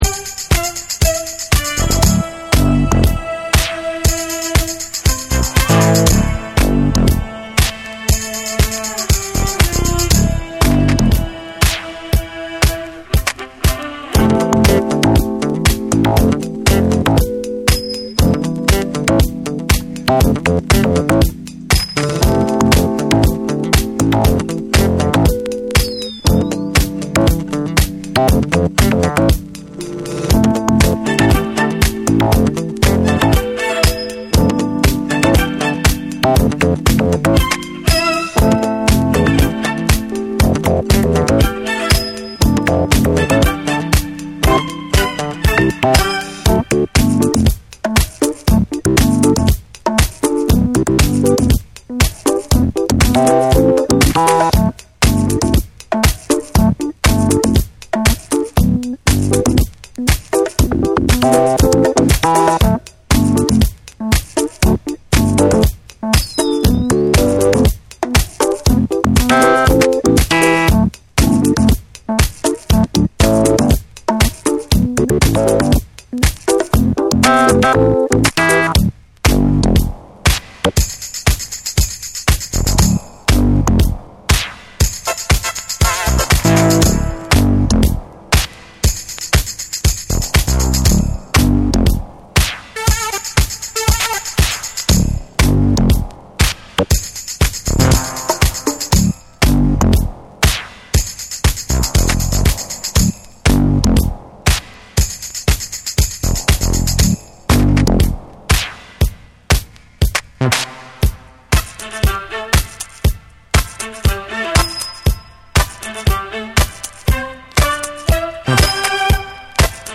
シンセ・ブギー／エレクトロ／ディスコを絶妙にブレンドした
ミニマルでグルーヴィーな展開が◎なDJユース仕様
TECHNO & HOUSE / DISCO DUB